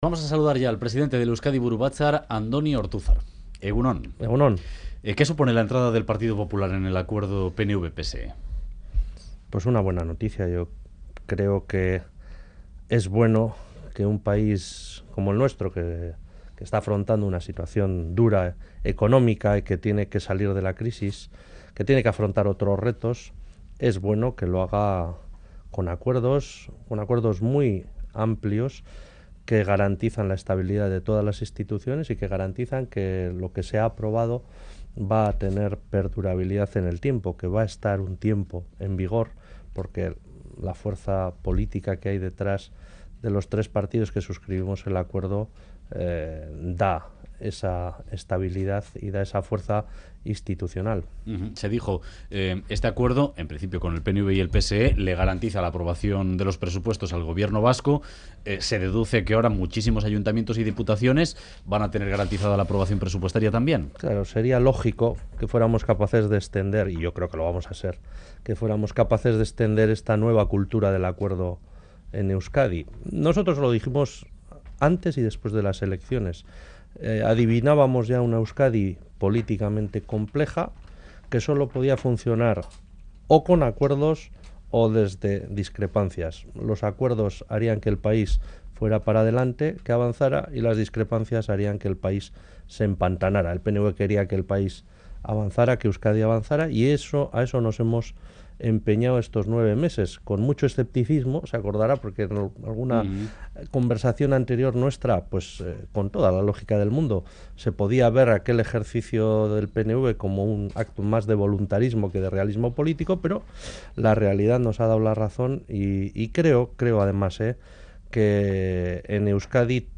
Radio Euskadi BOULEVARD Andoni Ortuzar se reafirma: en Euskadi habrá consulta Última actualización: 08/10/2013 10:45 (UTC+2) Entrevistado en el programa Boulevard de Radio Euskadi, el presidente del PNV, Andoni Ortuzar, ha acusado a EH de carecer de cintura política, y ha advertido que la reforma fiscal se aplicará también en Gipuzkoa.